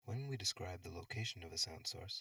male speaker
HRTF processed speech, 25 degrees to the left, early reverberation added